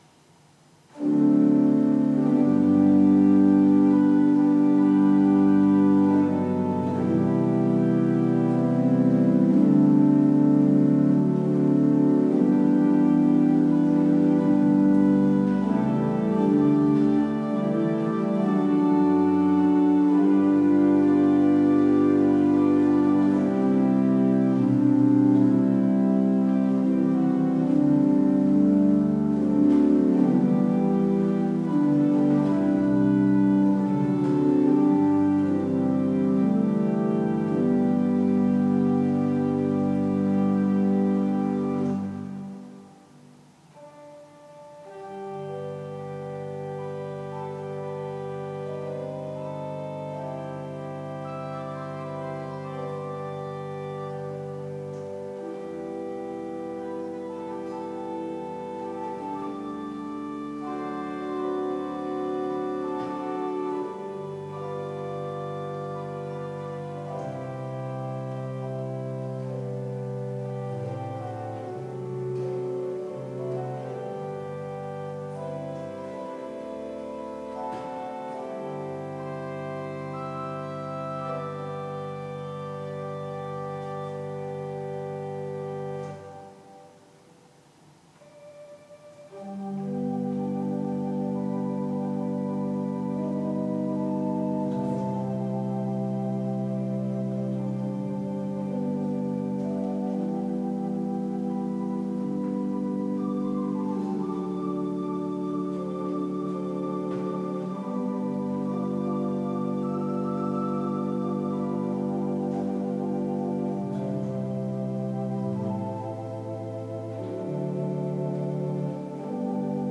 Kerkorgelmuziek